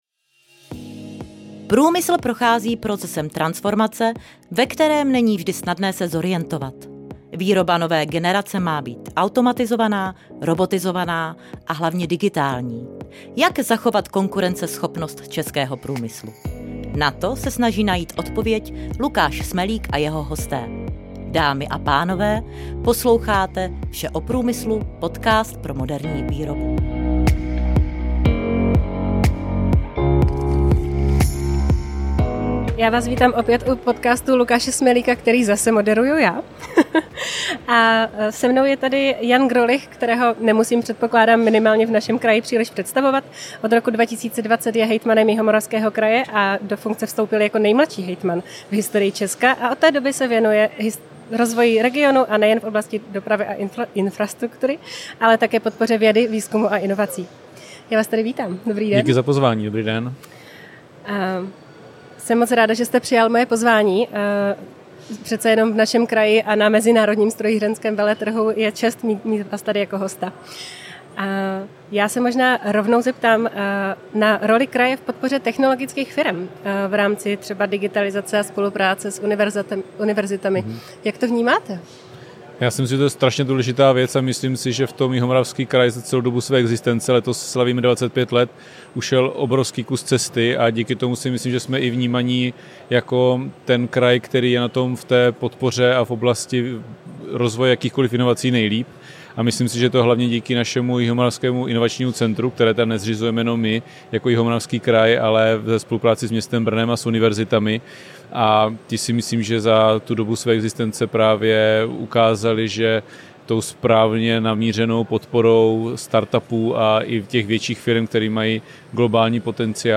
Pozvání do studia na MSV 2025 v Brně přijal jako další host Jan Grolich. Ten je od roku 2020 hejtmanem Jihomoravského kraje a do funkce se zapsal jako nejmladší hejtman v historii ČR.